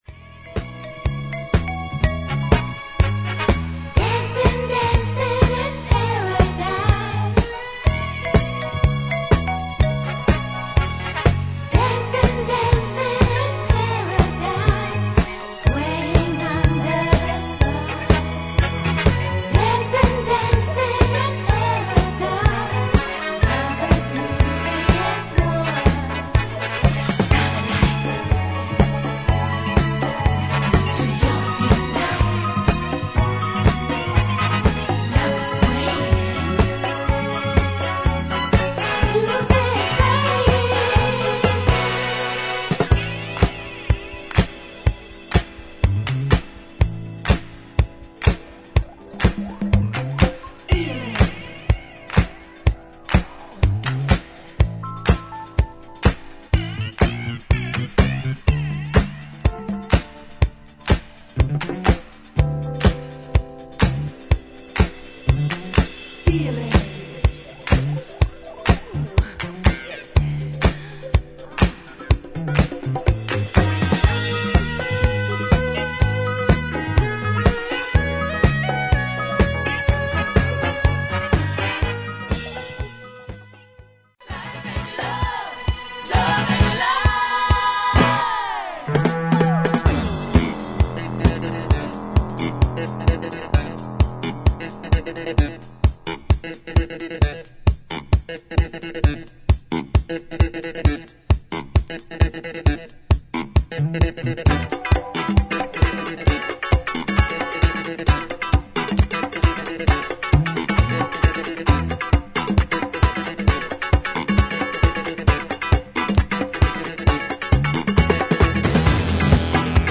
哀愁系ストリングスとシンセ・ワークが印象的なディスコ・ブギー
軽快でグルーヴィー、ファンキーかつパーカッシヴな